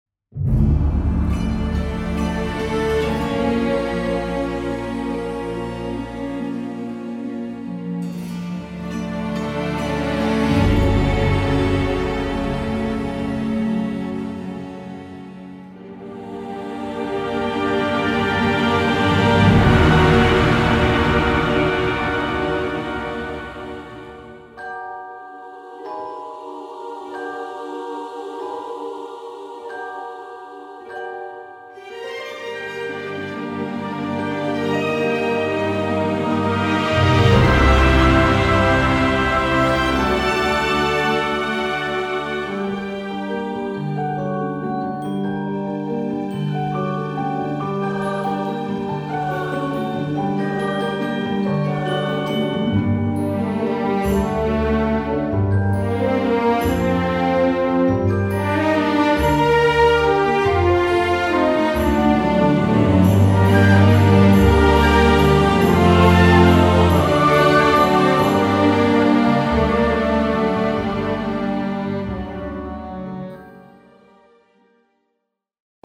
in the grand John Williams tradition